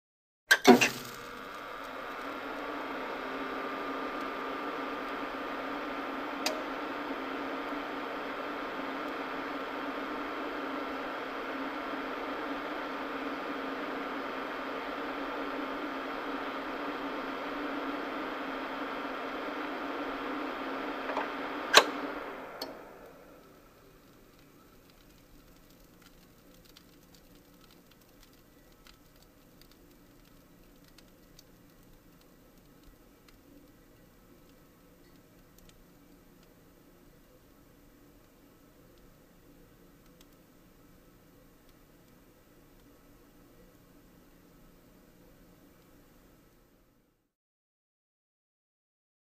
Mac Monitor; On / Off; Computer Monitor; Power On / Surge / Static / Steady Fan And Hum / Power Off / Static, Close Perspective.